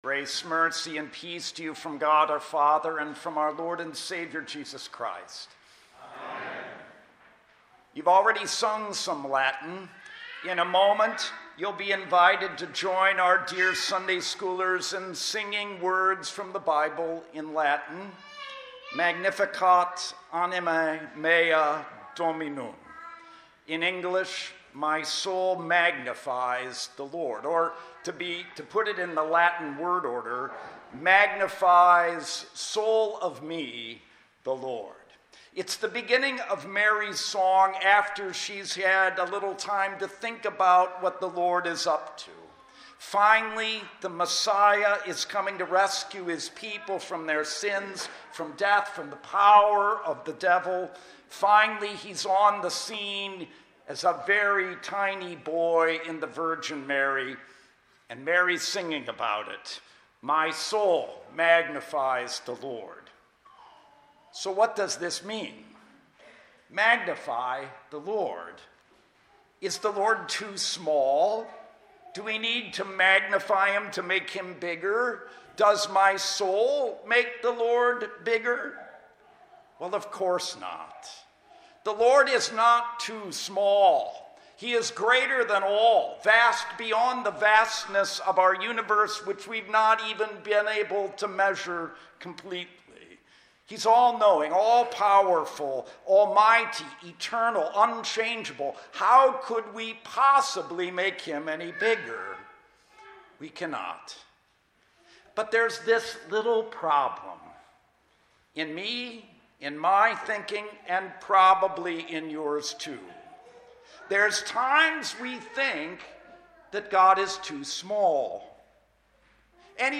Sunday School Advent Evening Prayer – Luke 1:46-55 – December 5, 2018